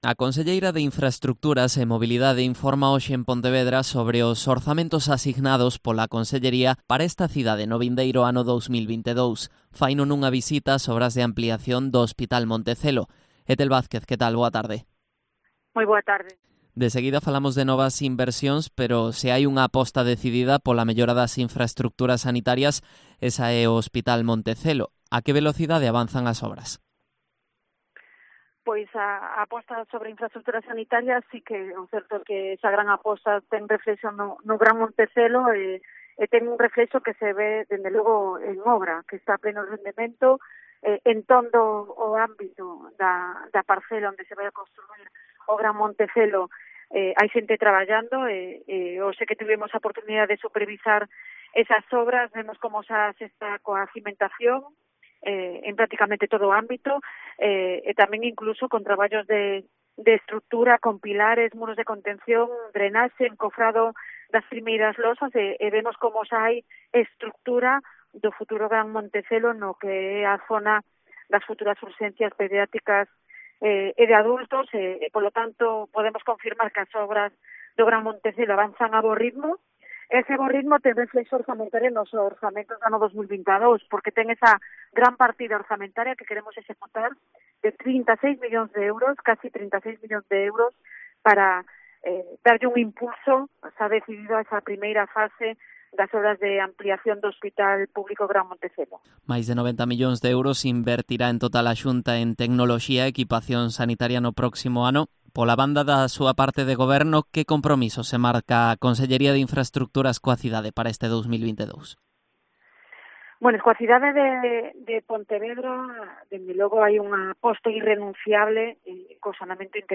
Entrevista a Ethel Vázquez, conselleira de Infraestructuras e Mobilidade